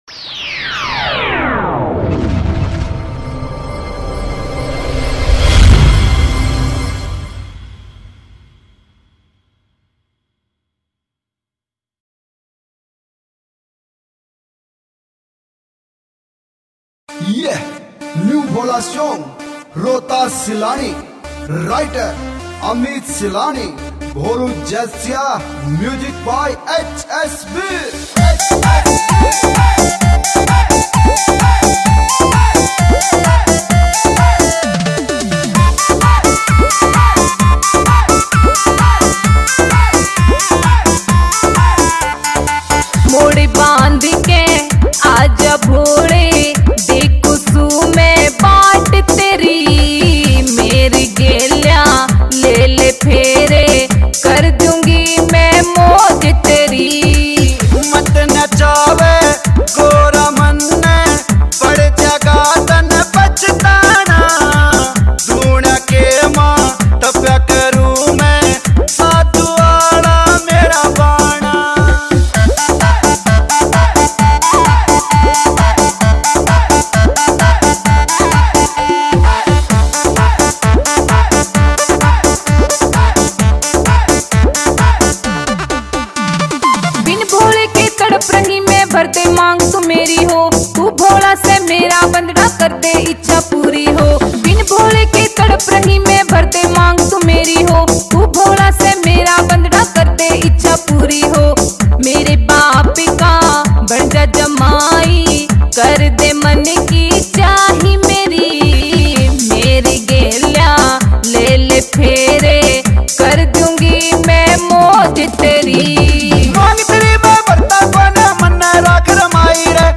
Haryanvi Songs 2021
Bhakti Songs